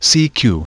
This new hf digital mode (digimode) has the same BPSK modulation of PSK31 but with a different coded FEC bitstream giving some enhancement against ionospheric fading and interchannel interferences.